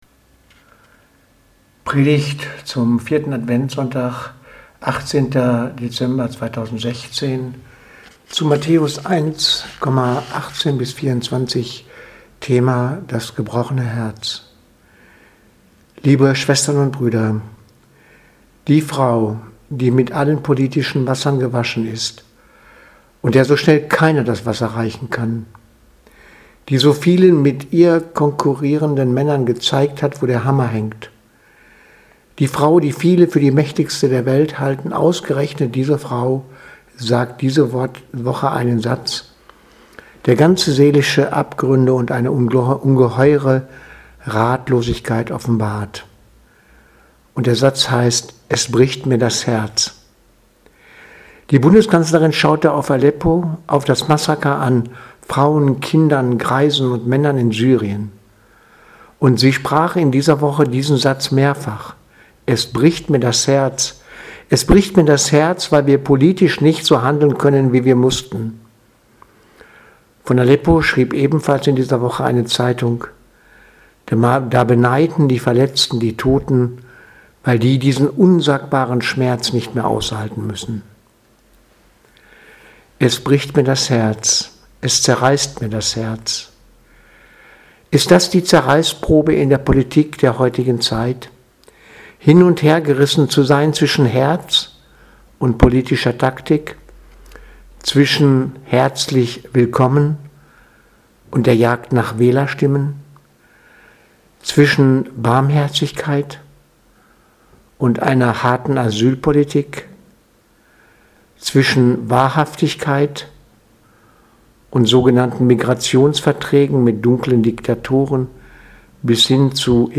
Predigt zum 4. Adventssonntag 2016-12-18